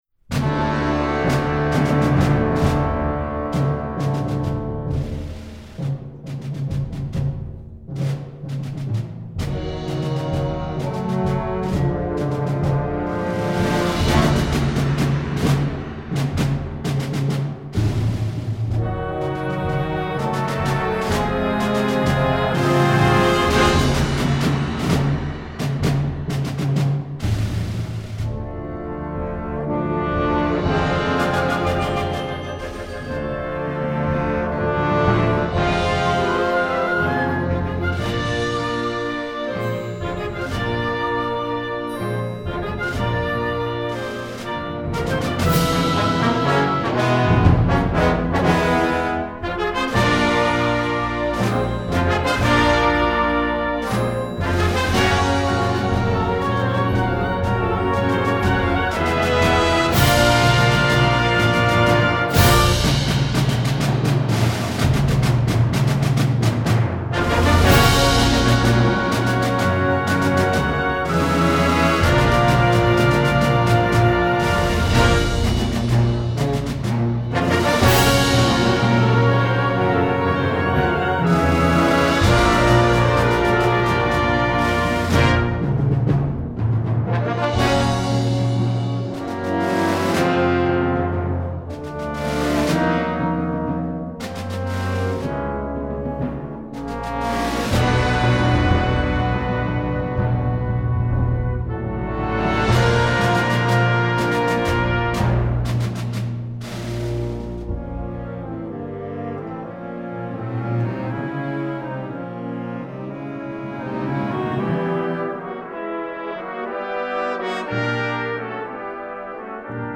Instrumentation: concert band